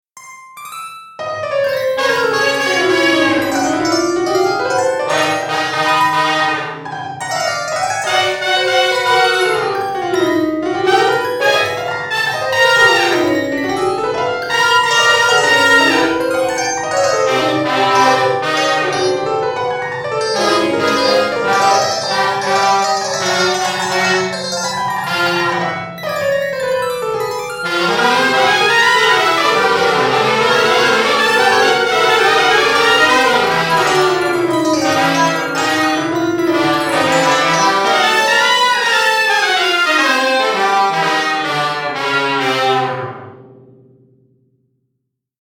I decided to try and make a piece using the GarbageBand software on my Mac, just using the mouse to input notes.